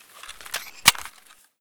holster.ogg